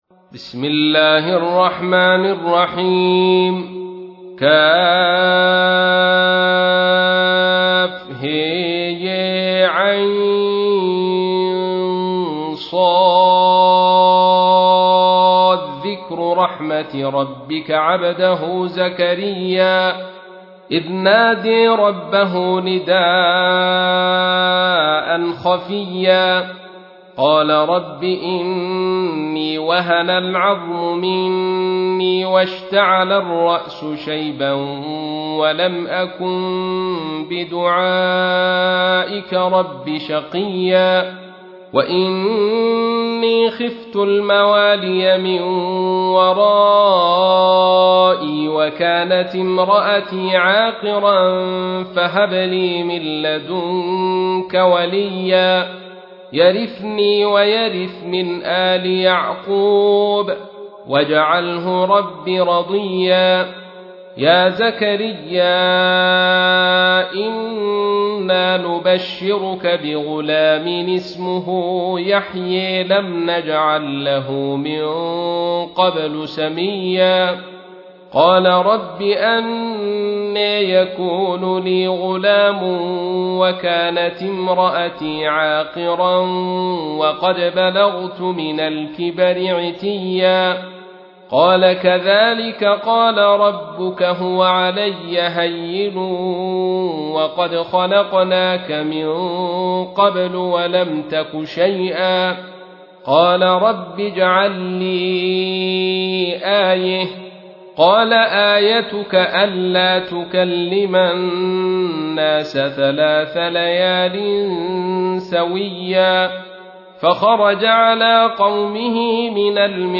تحميل : 19. سورة مريم / القارئ عبد الرشيد صوفي / القرآن الكريم / موقع يا حسين